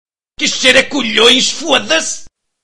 ruca que cheiro a colhoes fodasse Meme Sound Effect
Category: Reactions Soundboard